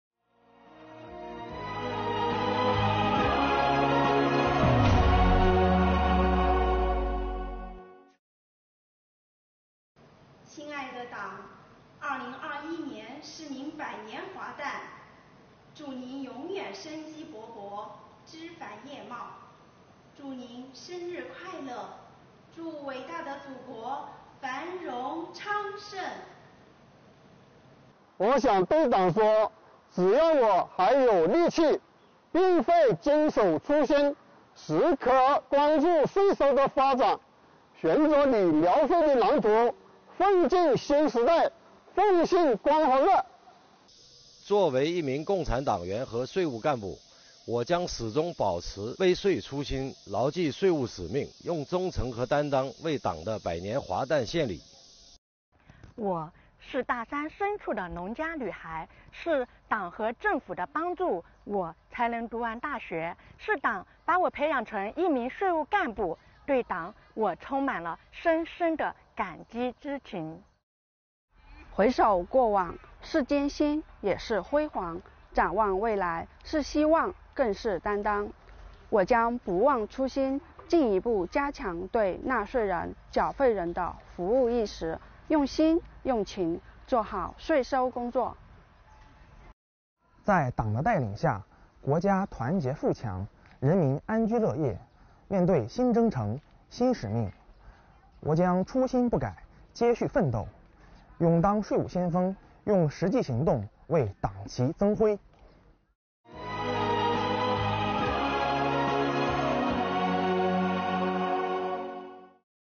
在建党百年之际，桂林市税务系统的广大党员干部满怀着对党和人民忠诚和热爱，向党深情告白，为党送上诚挚的祝福……